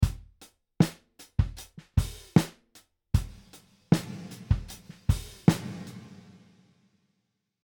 This set of examples has a full drum kit recorded with multiple mics in a dry room; only the snare drum gets reverb added to it. The drums play dry first and and then the reverb is added in the second part of each example.
Large Hall – Snare
As with the acoustic guitar examples, it’s pretty easy to hear the dramatic changes to the space surrounding the drum in the mix.
Snare_Large_Hall.mp3